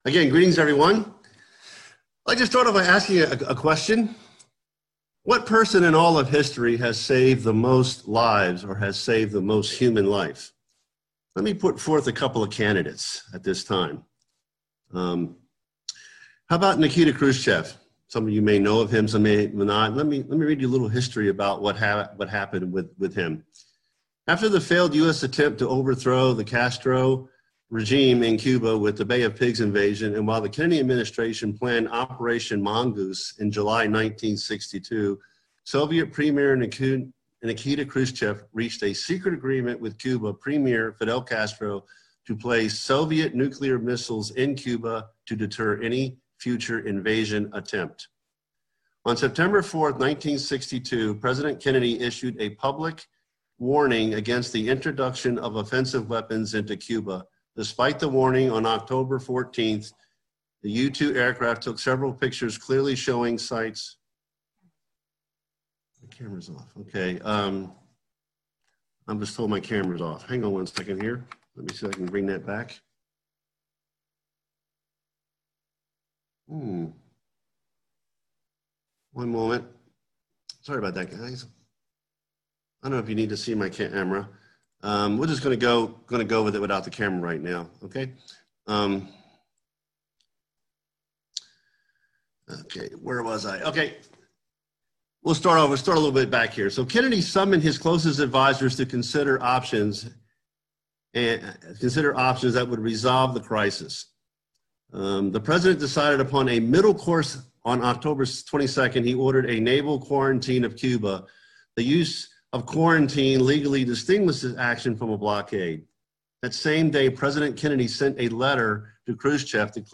Location: Orinda This message will explain who are the Elect. It will define the qualities of the Elect from how it is translated from the Greek in scripture. The message will cover God’s and our role in us becoming an Elect and how the tribulation will be cut short for the Elect sake.